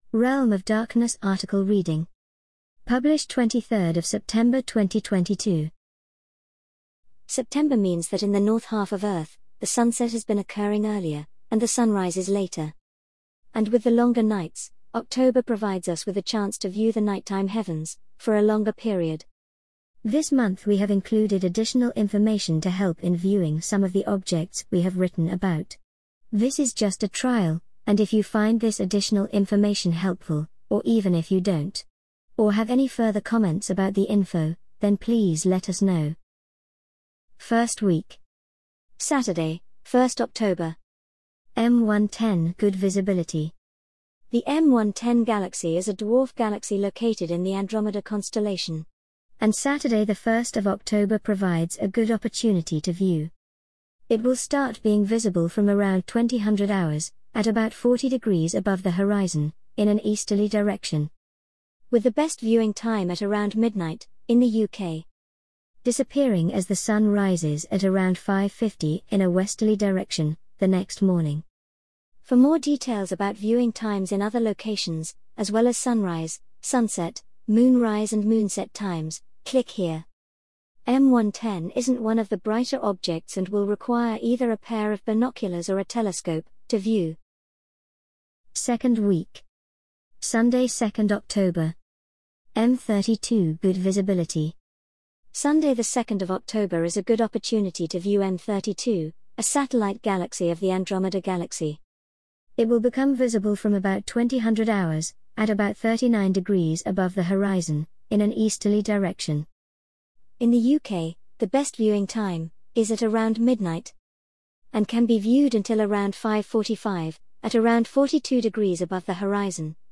An audio reading of the Realm of Darkness October 2022 Article